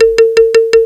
PERC LOOP2-R.wav